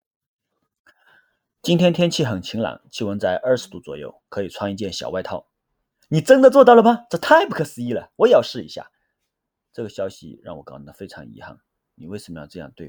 Autentyczny lektor w dialekcie Wenzhou
Zachowaj lokalną kulturę dzięki naturalnie brzmiącemu głosowi AI, zaprojektowanemu do narracji w dialekcie Wenzhou, edukacji i produkcji mediów regionalnych.
Oujiang Wu
Nasza sztuczna inteligencja zapewnia autentyczny, rodzimy ton, który precyzyjnie artykułuje specyficzne samogłoski i spółgłoski występujące tylko w tym regionie.